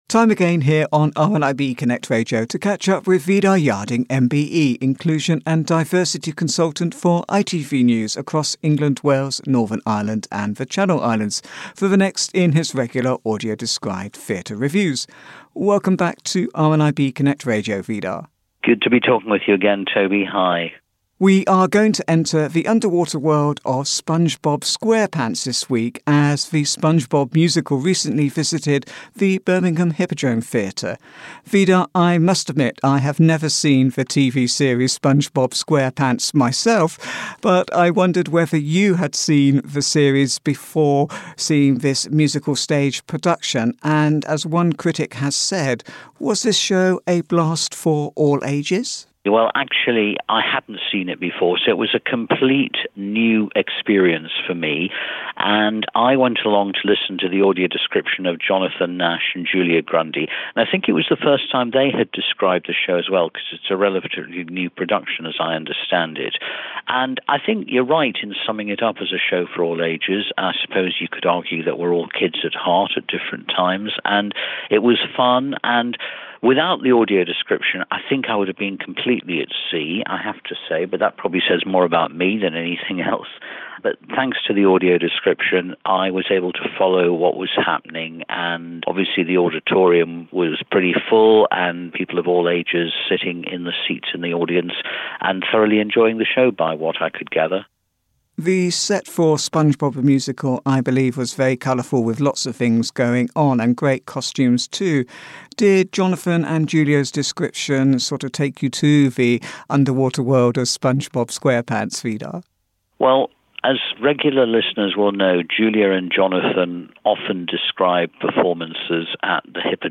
Theatre Review